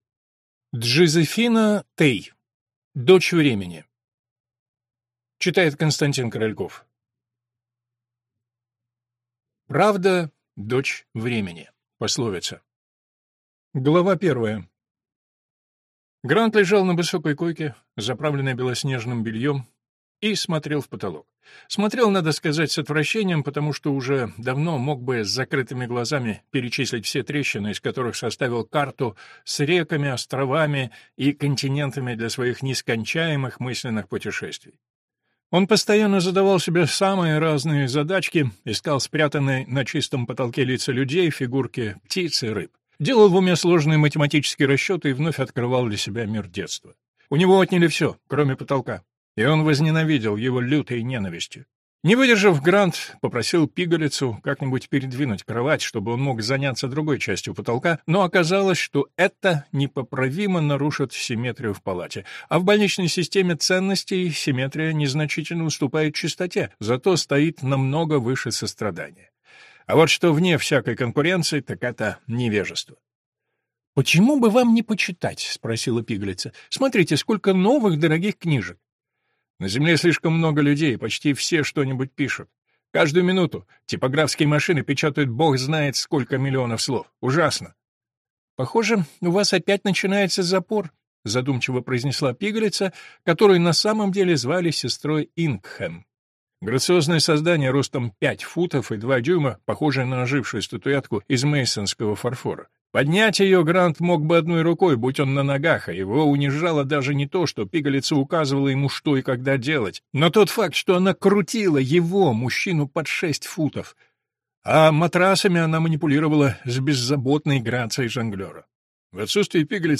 Аудиокнига Дочь времени. Поющие пески | Библиотека аудиокниг